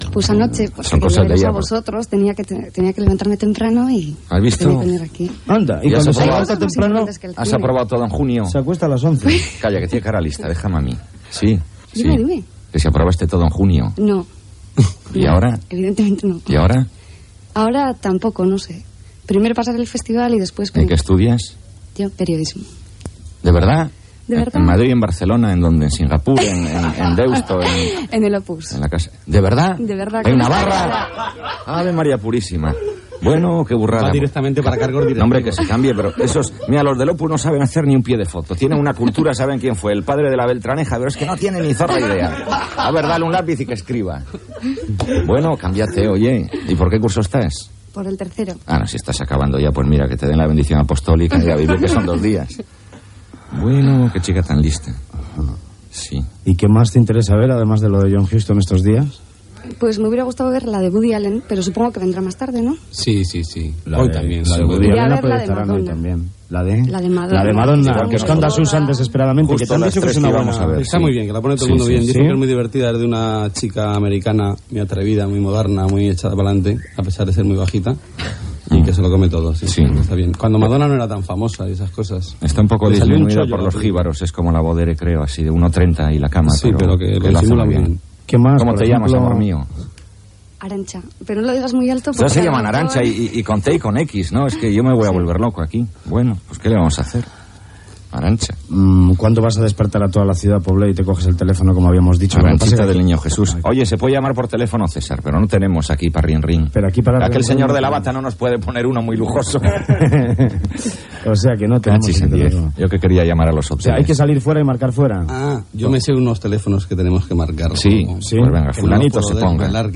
Espai fet des del Festival de Cienema de Sant Sebastià, amb una entrevista a una alumna de periodsime i comentaris de cinema